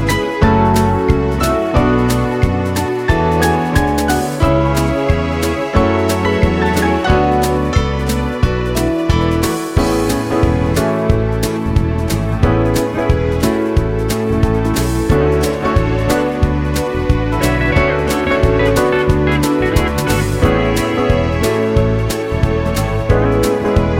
Soft Rock